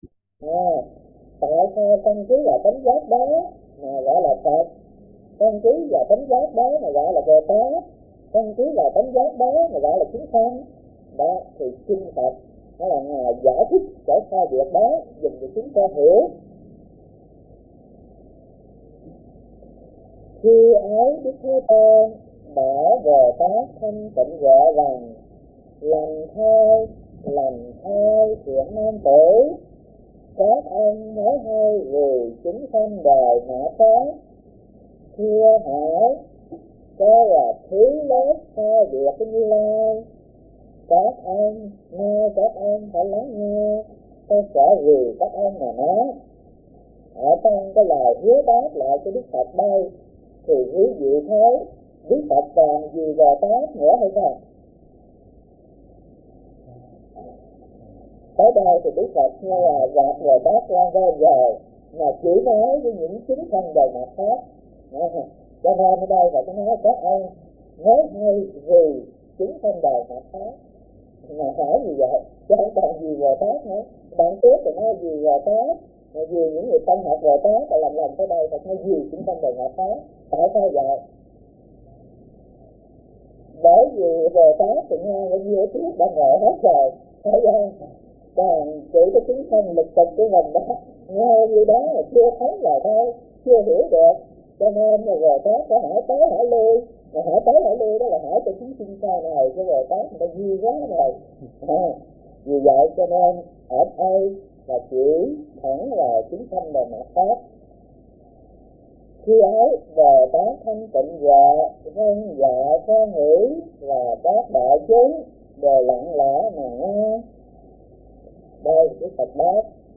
Kinh Giảng Kinh Viên Giác - Thích Thanh Từ